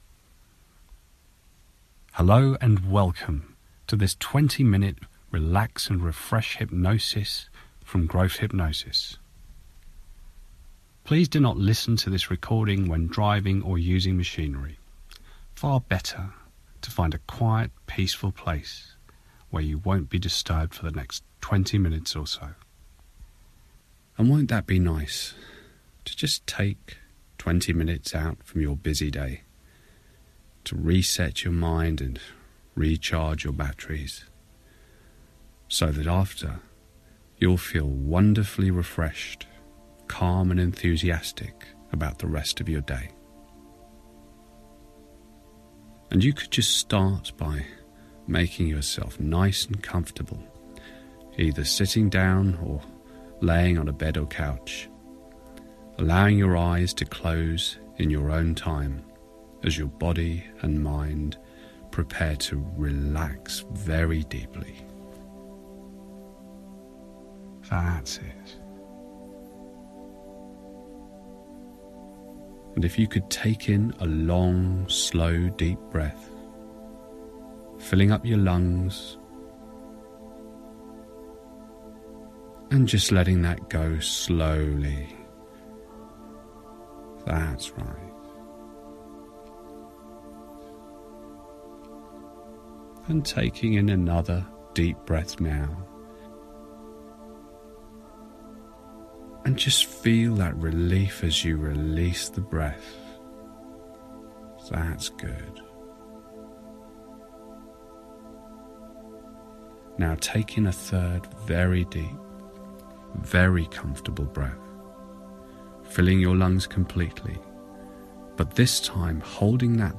Learn to relax every part of you with this 20 minutes self-hypnosis audio.